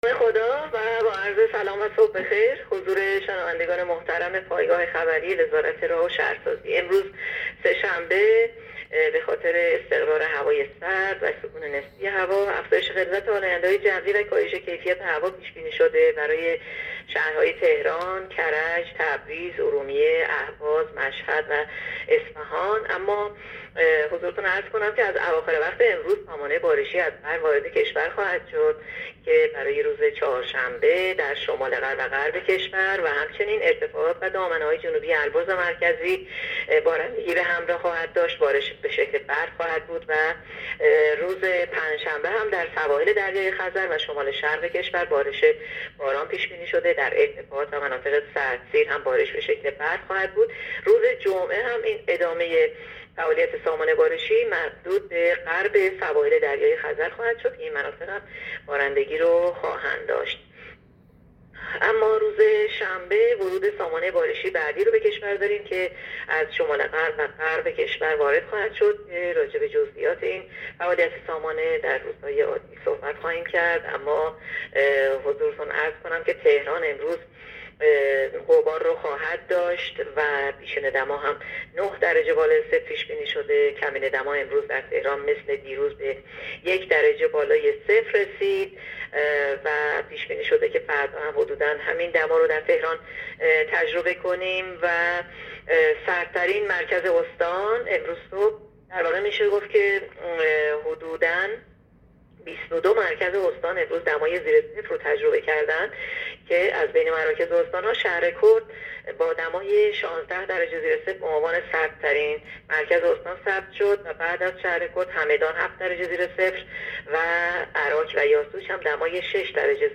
گزارش رادیو اینترنتی از آخرین وضعیت آب و هوای دوم دی؛